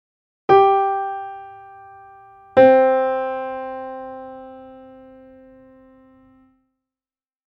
fifth-resolves-to-root.mp3